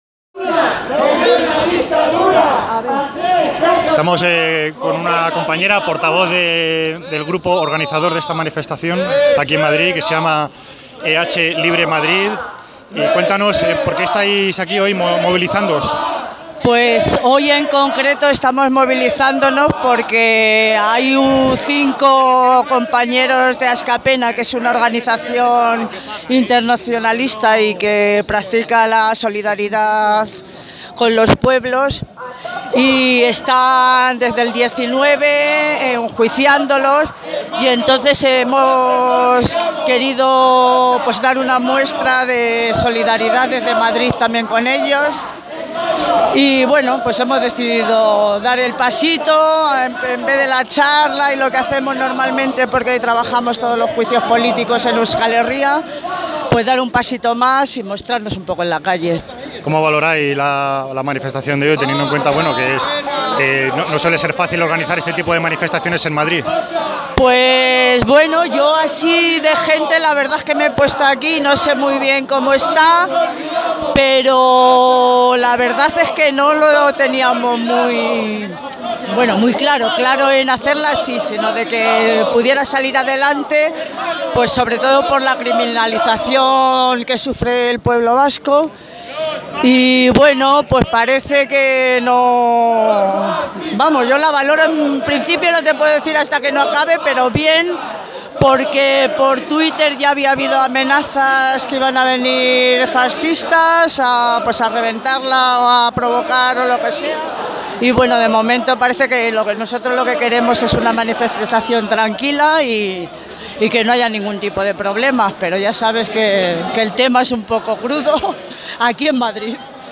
Entrevista a la Plataforma EH Libre Madrid, convocante de la marcha: